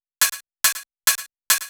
VTDS2 Song Kit 07 Female Play Girl Open Hihat.wav